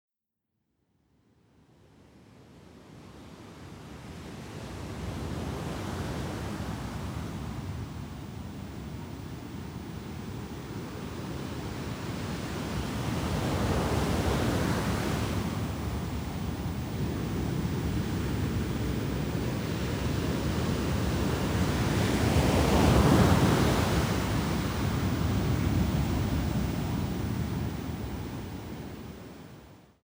Listen to a sample of the ocean track